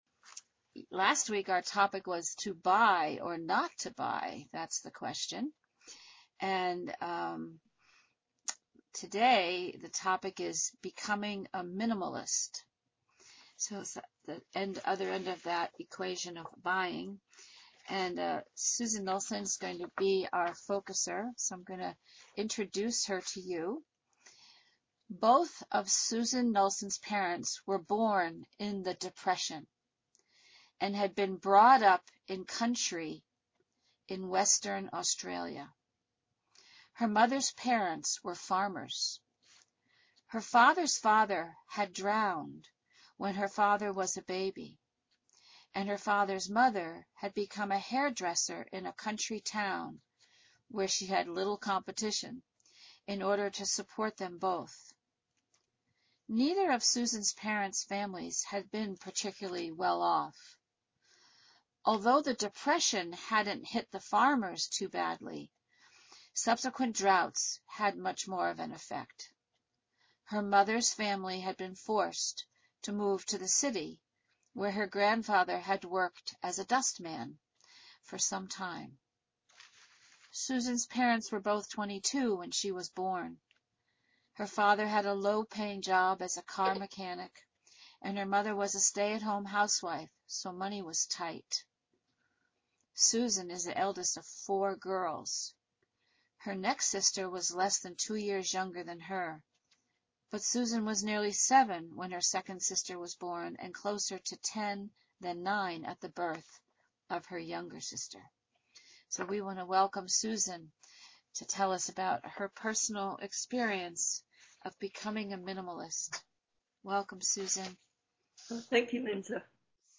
Focus talk